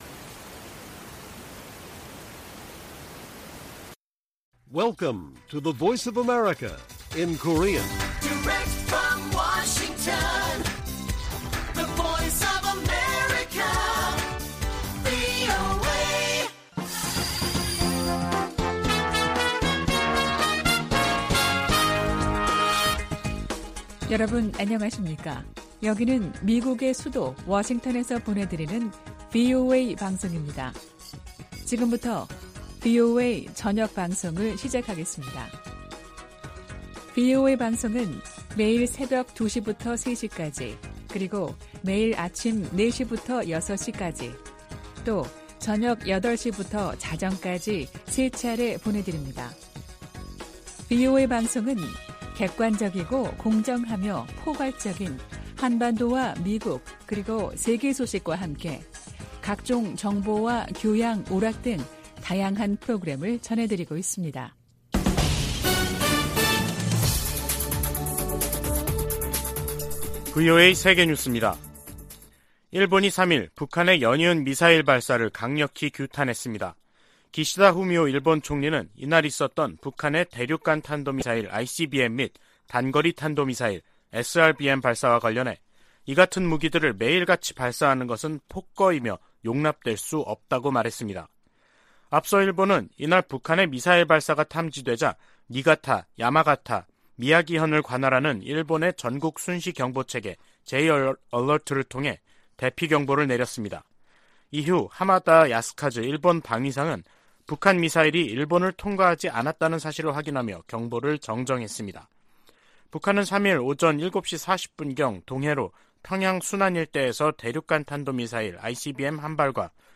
VOA 한국어 간판 뉴스 프로그램 '뉴스 투데이', 2022년 11월 3일 1부 방송입니다. 북한 김정은 정권이 어제 동해와 서해상에 미사일과 포탄을 무더기로 발사한 데 이어 오늘은 대륙간탄도미사일, ICBM을 쏘면서 도발 수위를 높였습니다. ICBM 발사는 실패한 것으로 추정된 가운데 미국과 한국 정부는 확장 억제 실행력을 높이면서 북한의 어떠한 위협과 도발에도 연합방위태세를 더욱 굳건히 할 것을 거듭 확인했습니다.